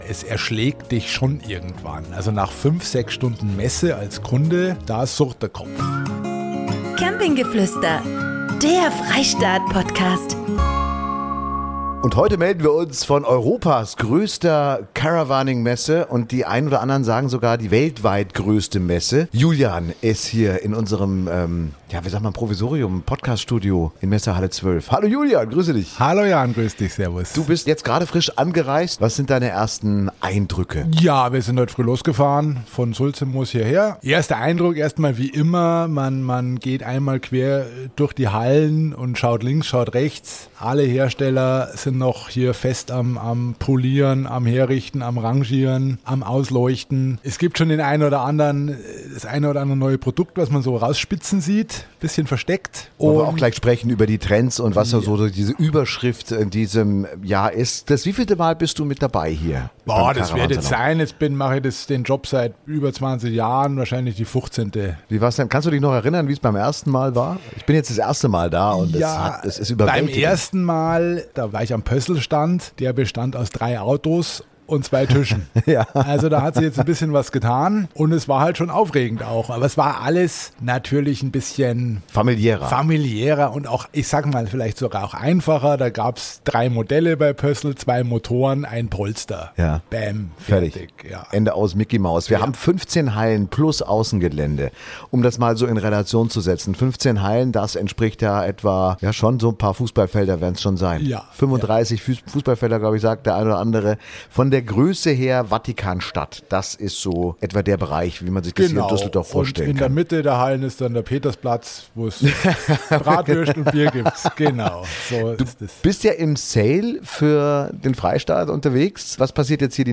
Wir melden uns direkt vom Caravan Salon 2025 in Düsseldorf und liefern die spannendsten Neuheiten: smarte Technik, frische Grundrisse, kreative Ideen fürs Vanlife und alles, was die Campingwelt gerade bewegt. Camping Geflüster liefert euch den Überblick – klar, konkret und mit echtem Messefeeling.